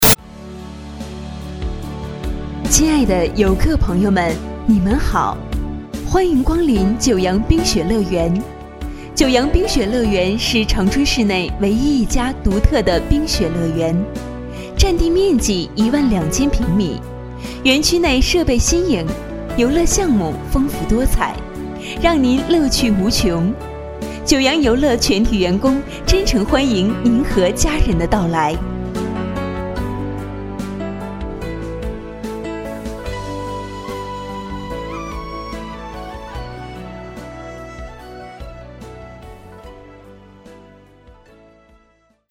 移动水上乐园之九洋冰雪乐园广播
移动水上乐园之九洋冰雪乐园景区介绍广播词